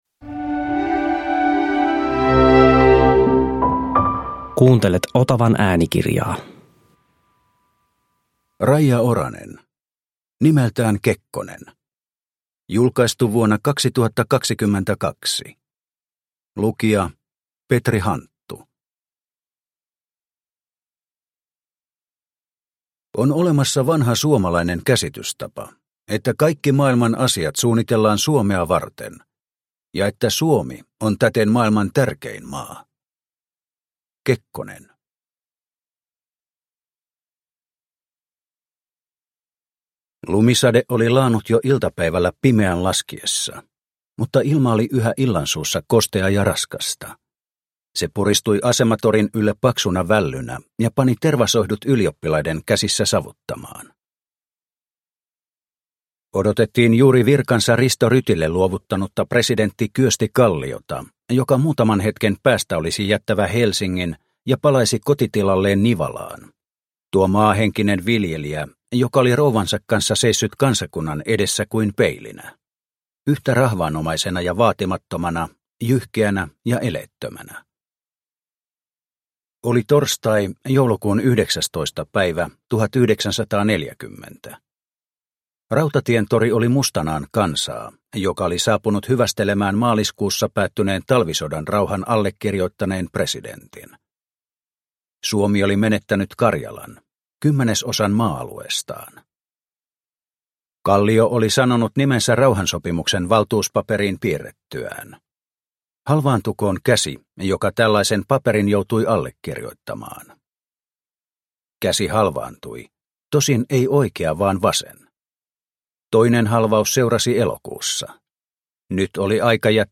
Nimeltään Kekkonen – Ljudbok – Laddas ner